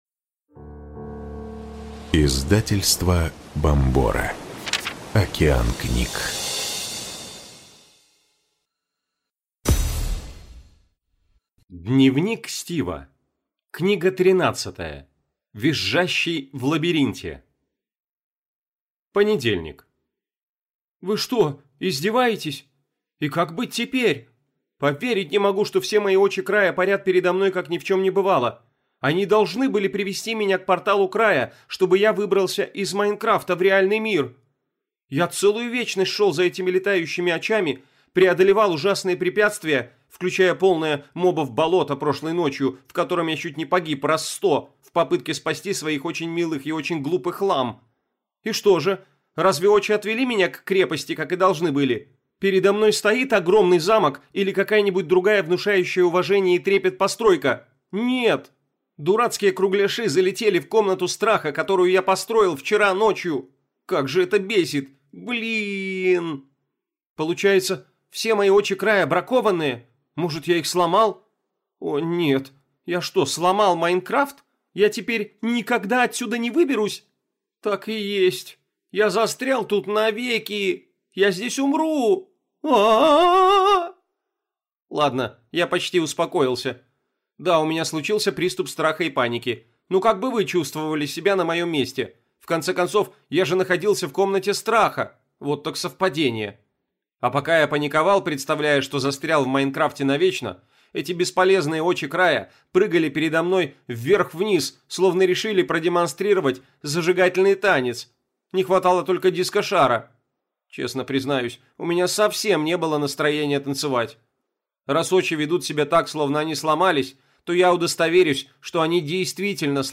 Аудиокнига Визжащий в лабиринте | Библиотека аудиокниг